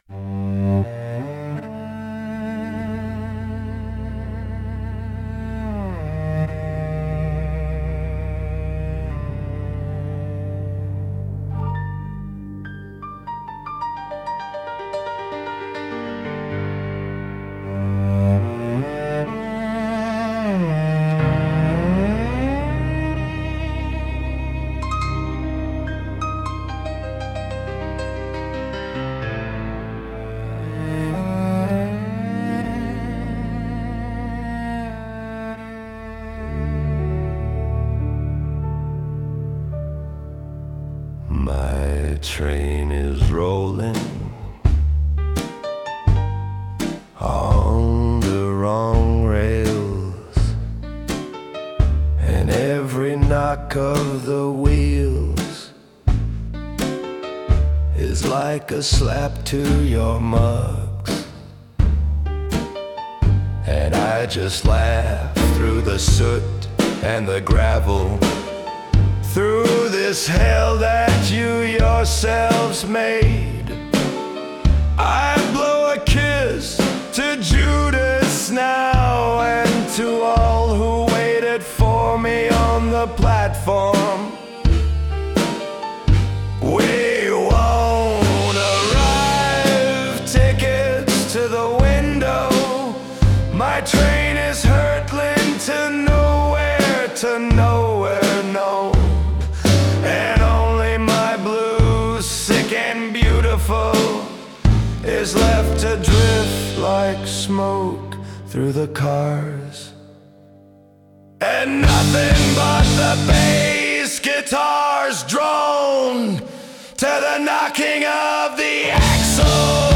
• Исполняет: Поставторcкий арт
Aristocratic cabaret-symphony-chanson.
Nervous, heart-rending blues, dressed in the strict tailcoat of a symphonic arrangement.